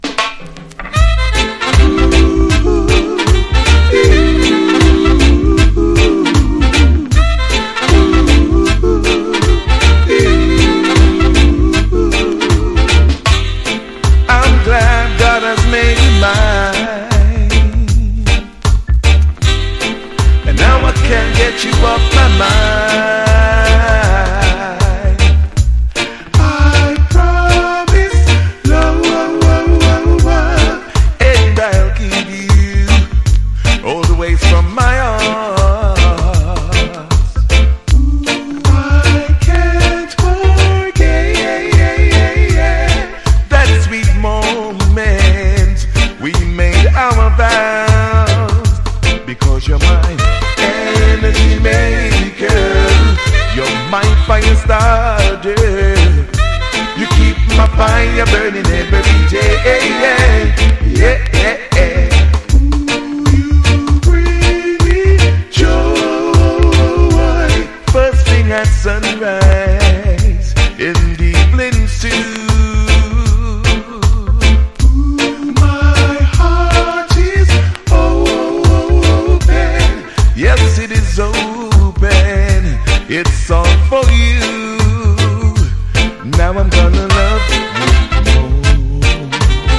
• REGGAE-SKA
SKA / ROCK STEADY